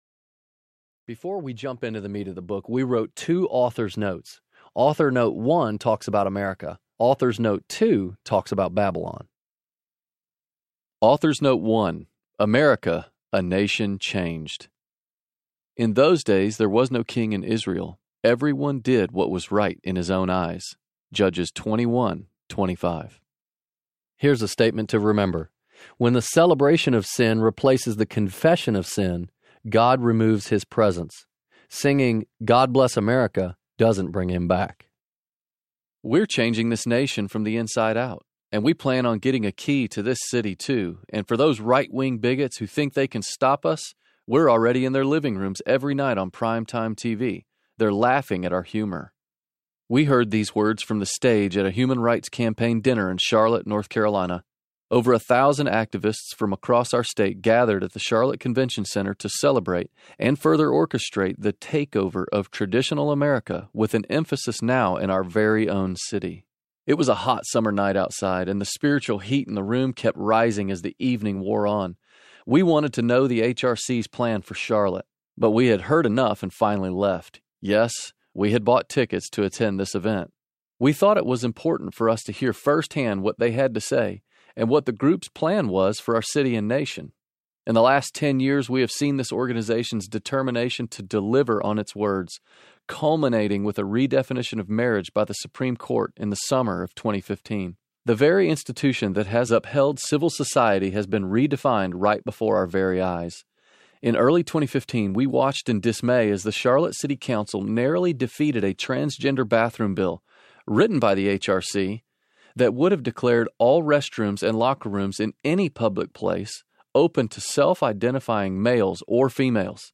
Living Among Lions Audiobook
5.9 Hrs. – Unabridged